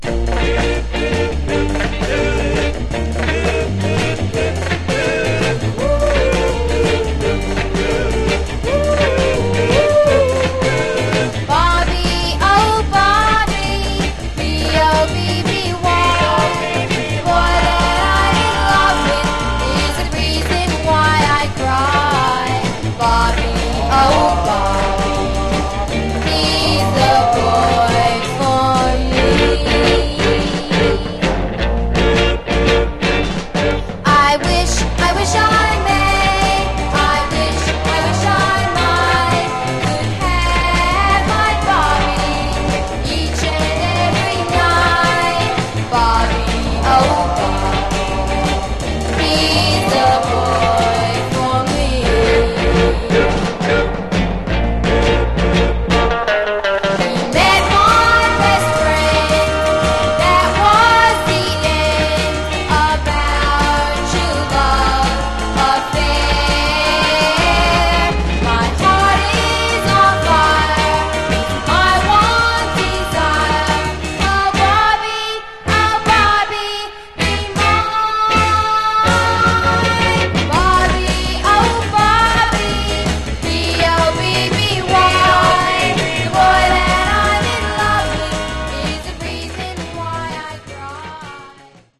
Genre: Girl Group